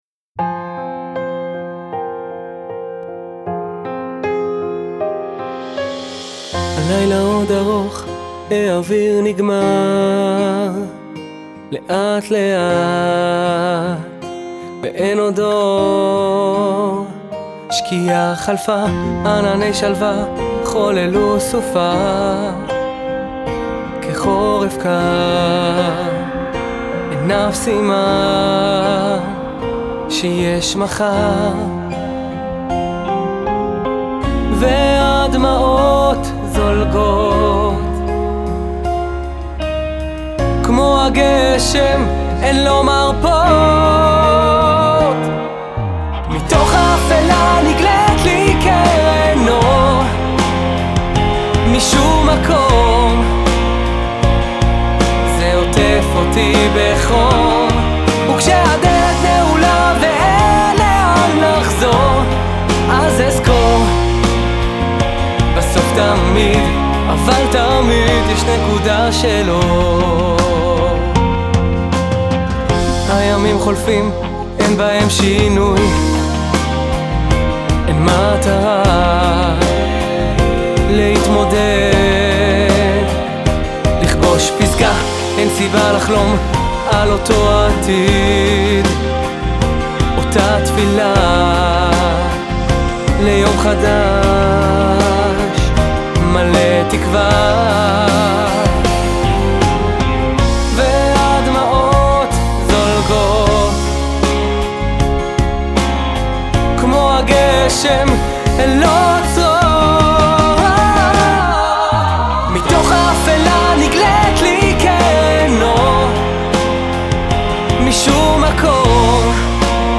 שירים חסידיים
בלי עין הרע איזה קול